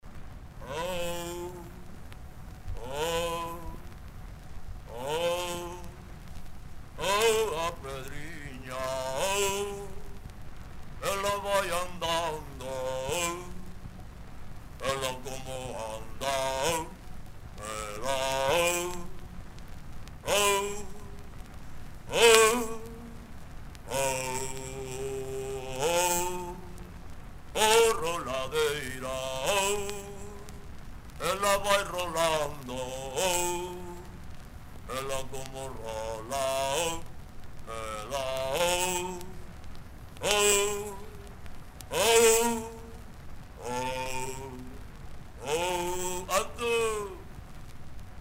Agora, realizaremos diferentes accións libremente polo espazo para habituarnos á lentitude do movemento que suporía desprazar unha pedra e para, a través dese movemento, tomar conciencia da métrica cuaternaria da peza.